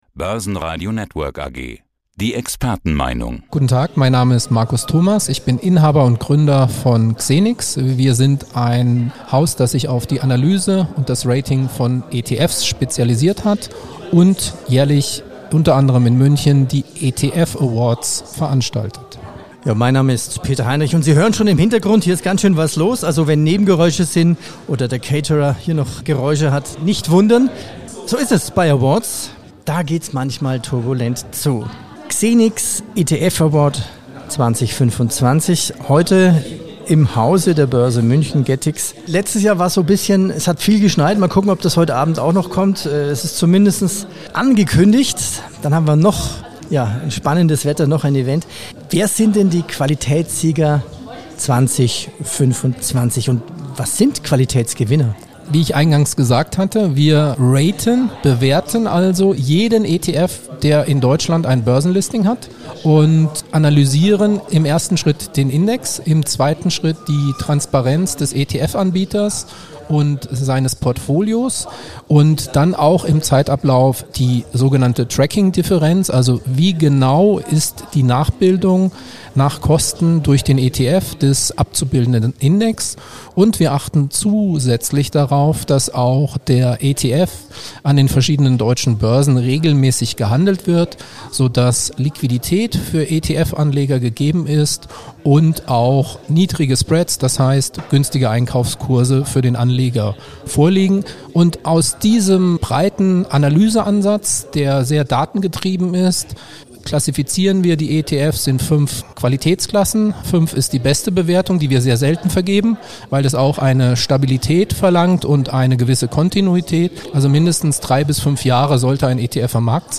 im Rahmen der ETF Awards in München. Es gibt fünf Qualitätsklassen, Xenix begutachtet unter anderem die Kosten.